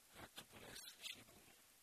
Pronunciation: ka:tʃəpu:nes-ʃi:pu:
Pronunciation